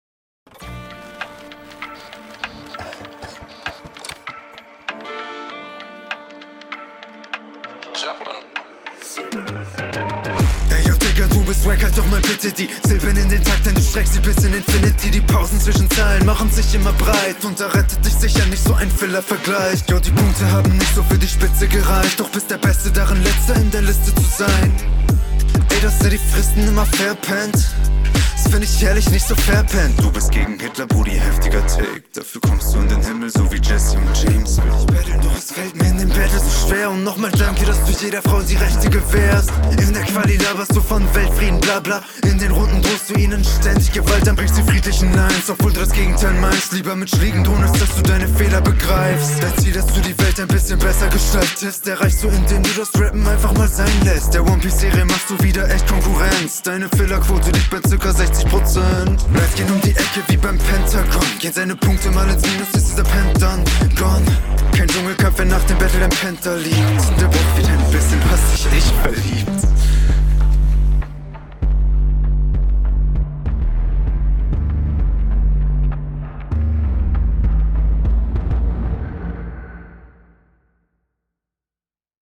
Was ein gutes Soundbild.
Flow kommt gut auf dem Beat, schöner Stimmeinsatz, nette Wortspiele mit dem …